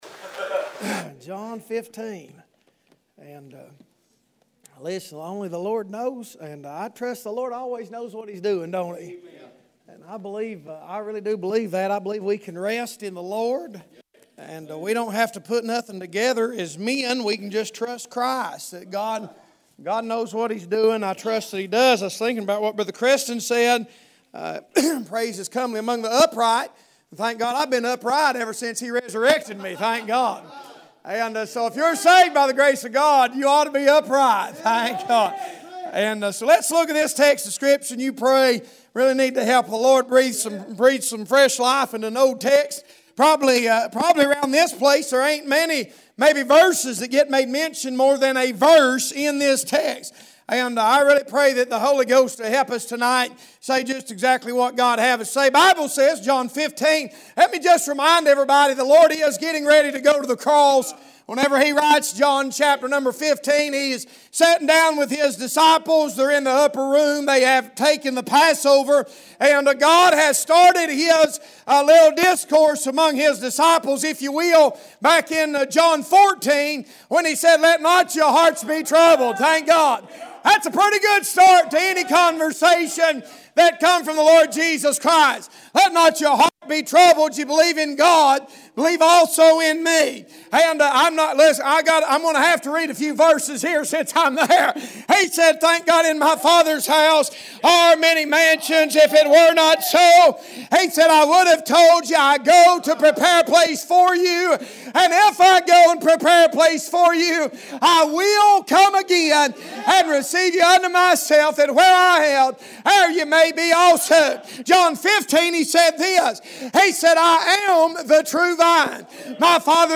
Passage: John 15:1-6; 16:33 Service Type: Wednesday Evening